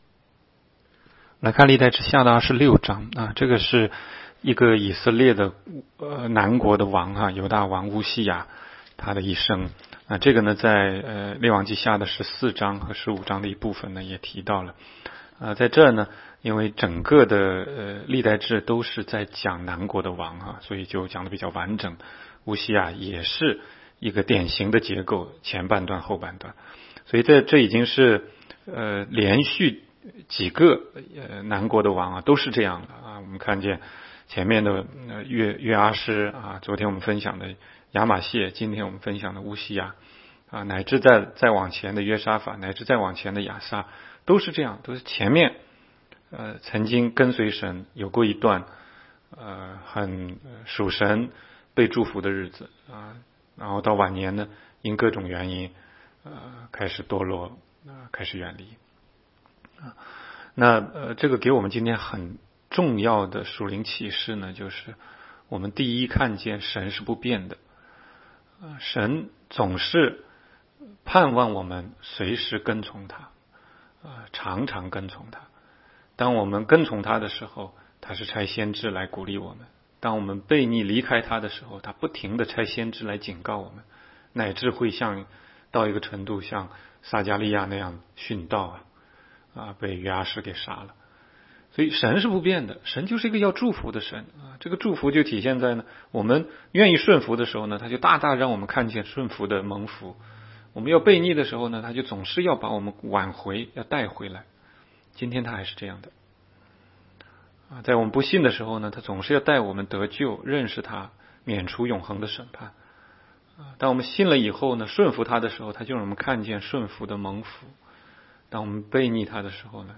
16街讲道录音 - 每日读经-《历代志下》26章
每日读经